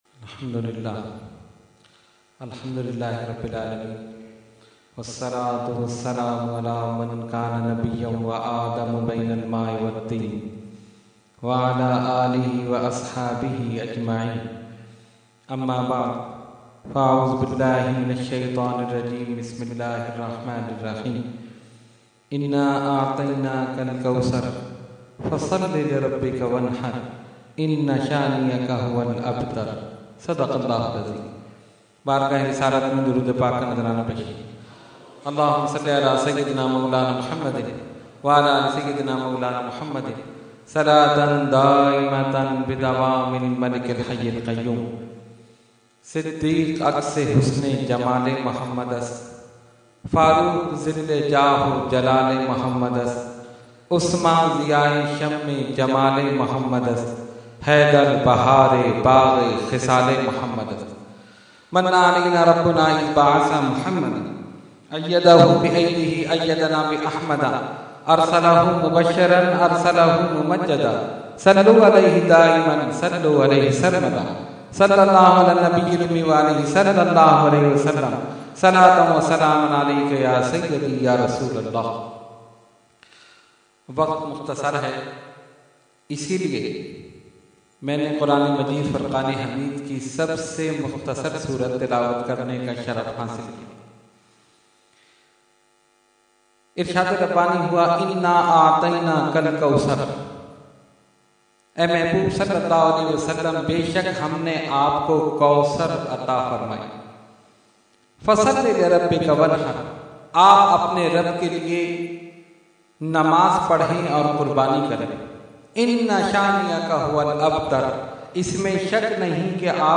Category : Speech | Language : UrduEvent : Subhe Baharan 2015